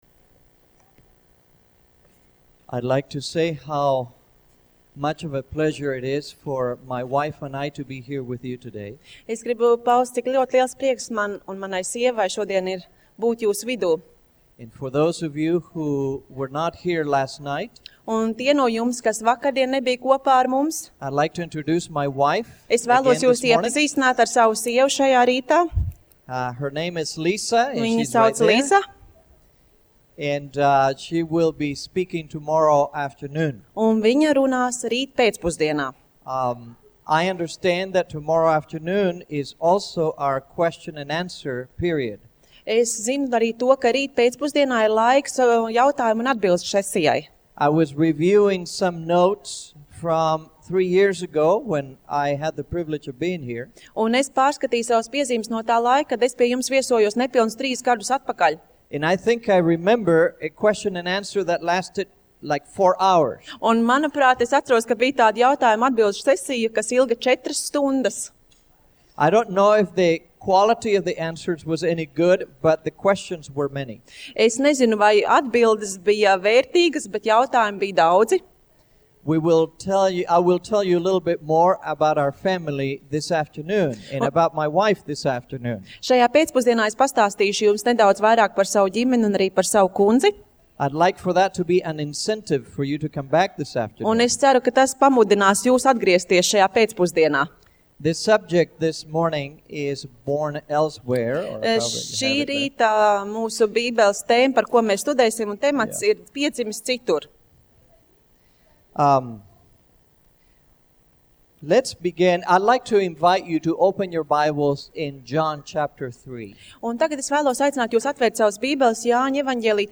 Seminārs